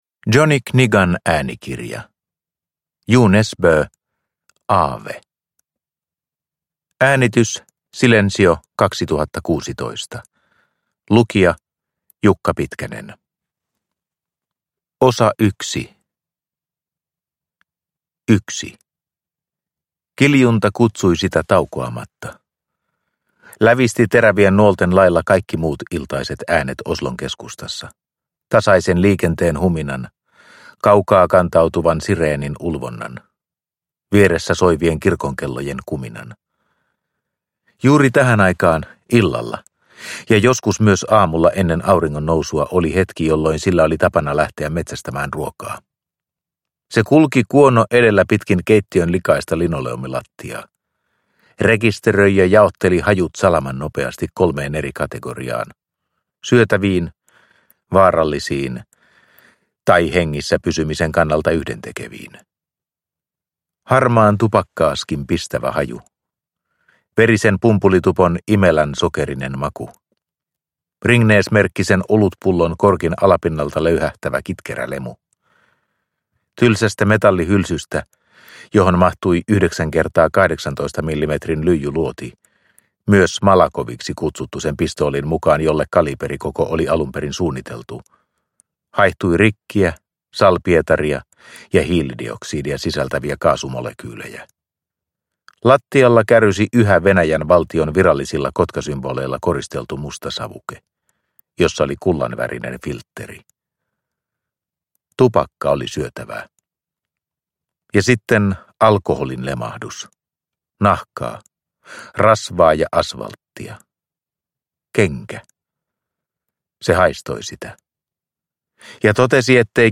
Aave – Ljudbok